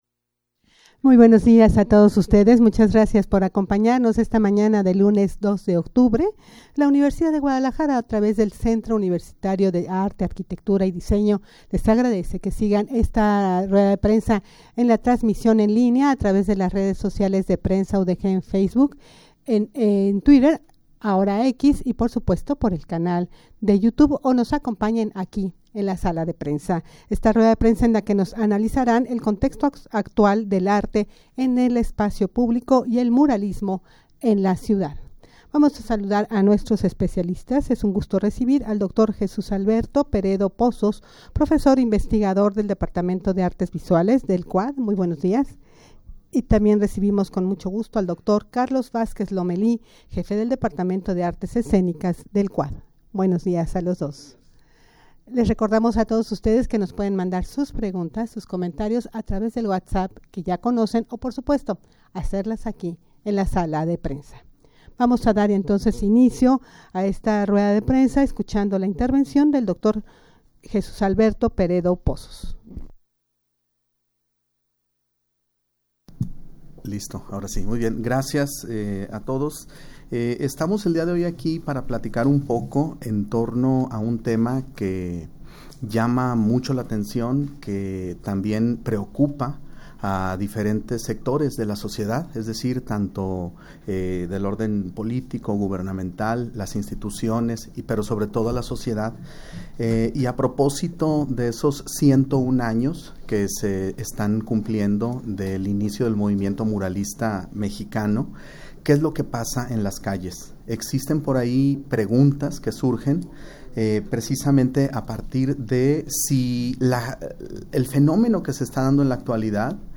rueda-de-prensa-sobre-el-contexto-actual-del-arte-en-el-espacio-publico-y-el-muralismo-en-la-ciudad.mp3